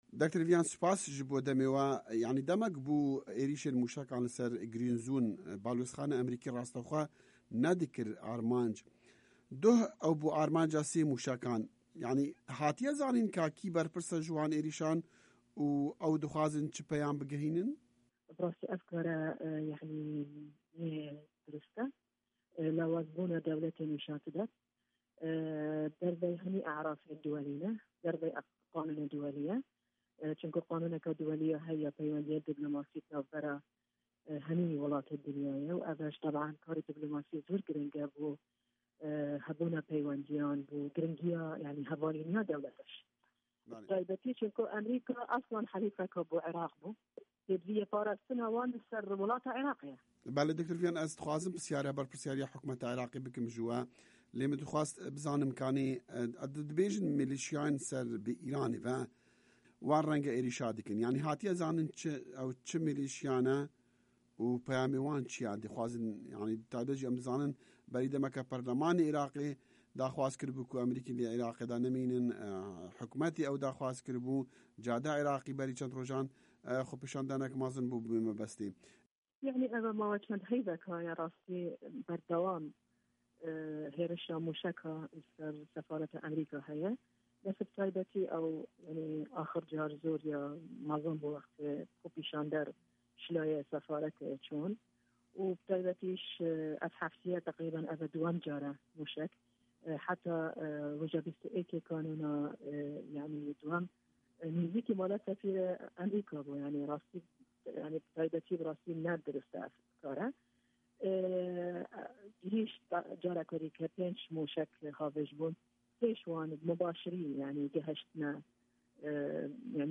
Hevpeyvin digel Viyan Sebri